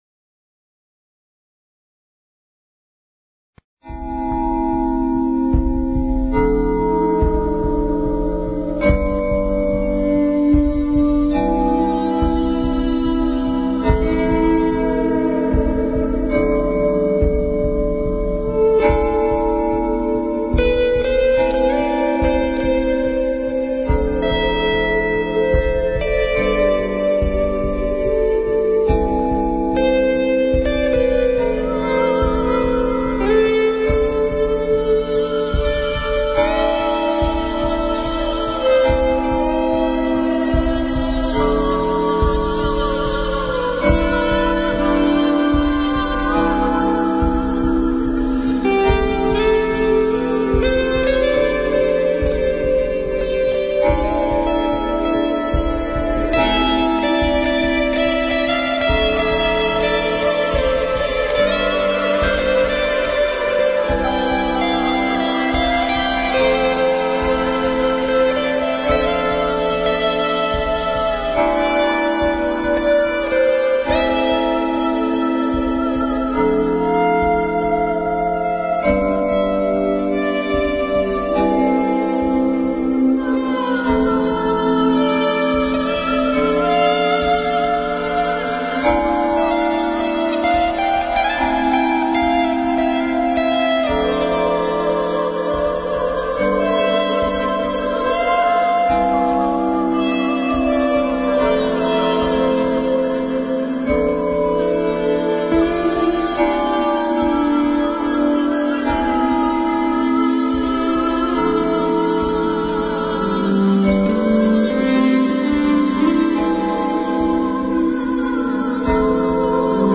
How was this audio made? We love call in questions!!!